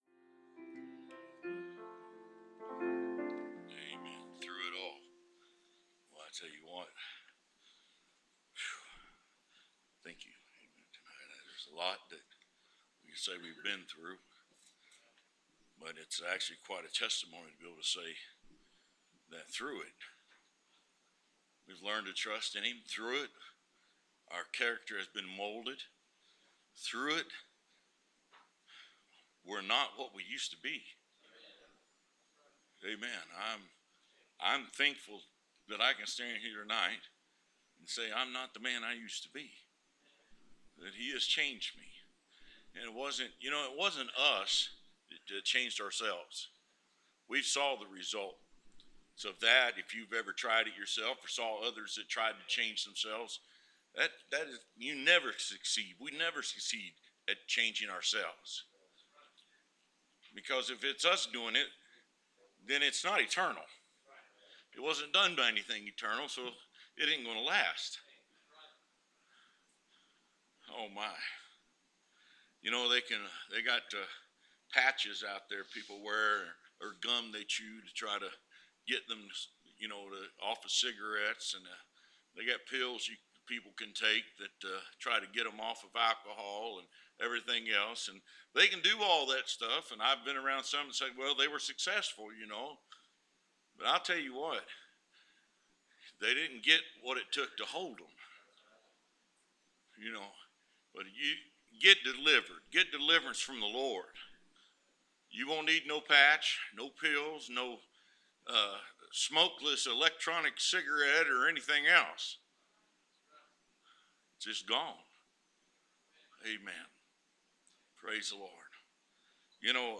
Preached 20 March 2014